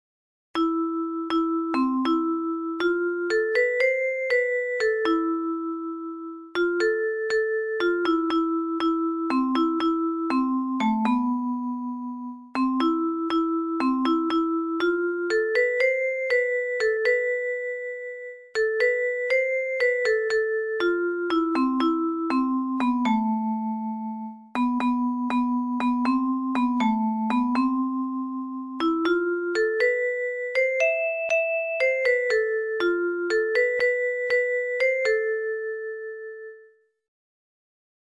ﾋﾞﾌﾞﾗﾌｫﾝ